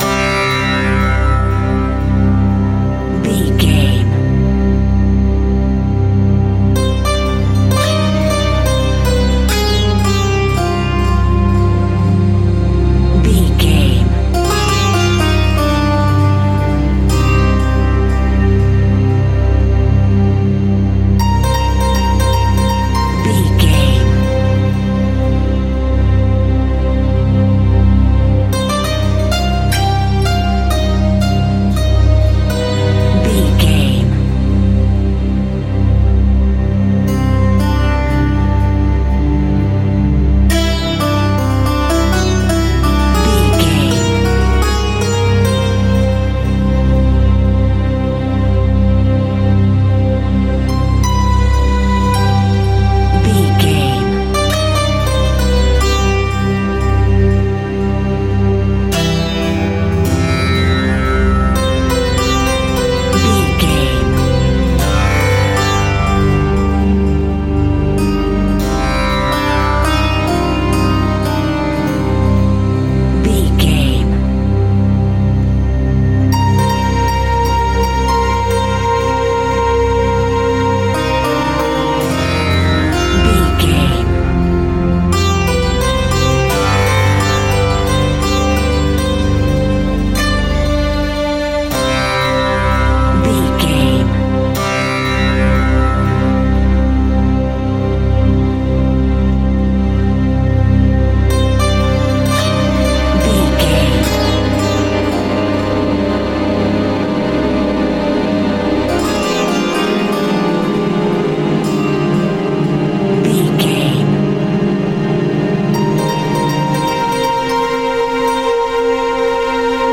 Aeolian/Minor
SEAMLESS LOOPING?
Slow
World Music